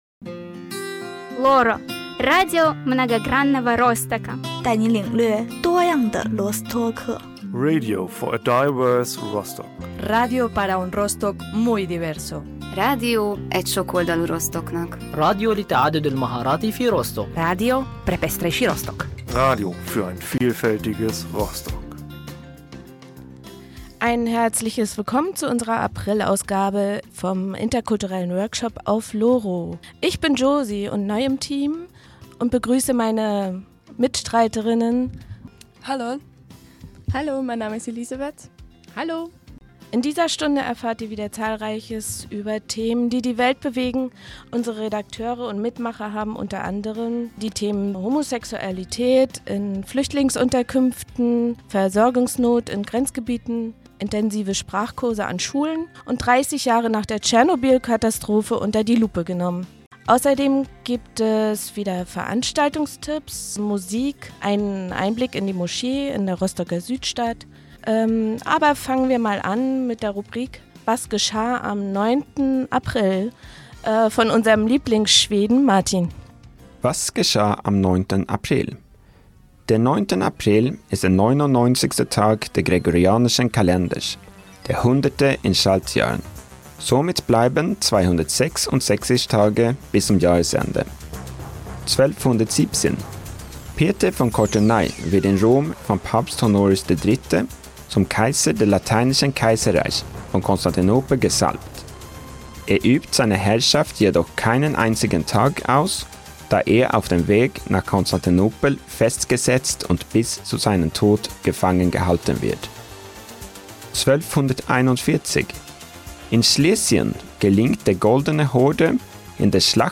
Die Sendung vom 09. April 2016 zum Nachhören, nur ohne Musik: